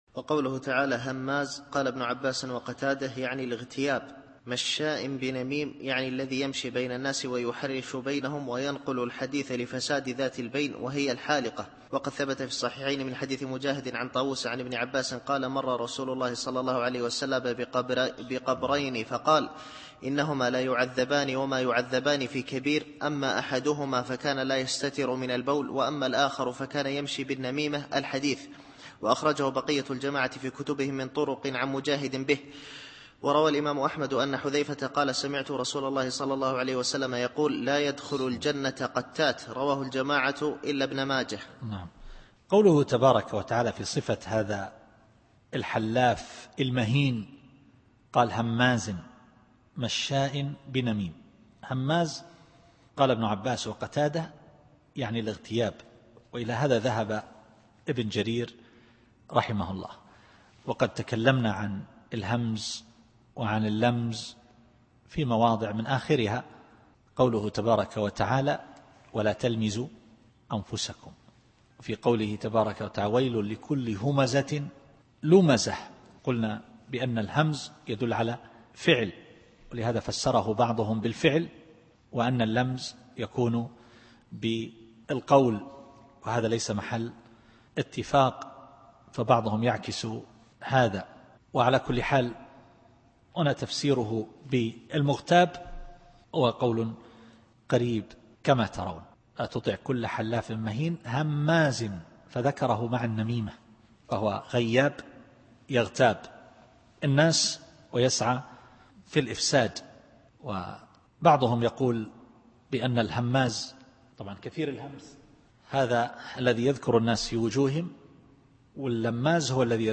التفسير الصوتي [القلم / 11]